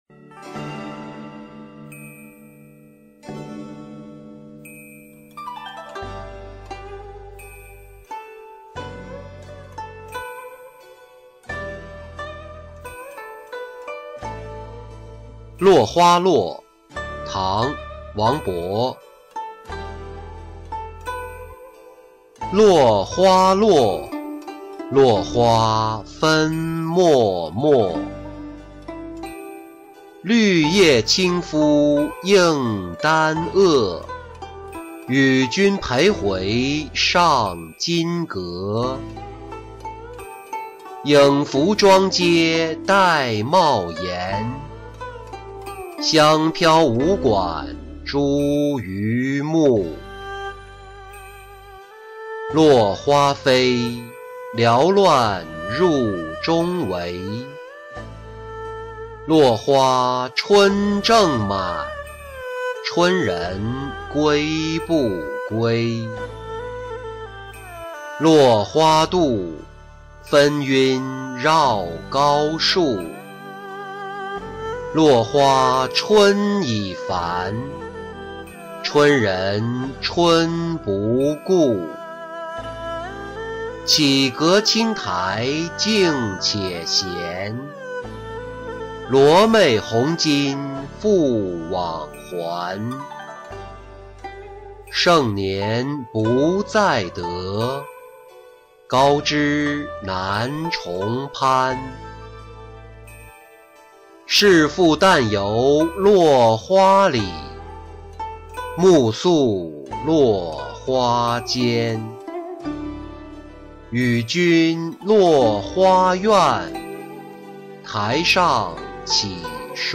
落花落-音频朗读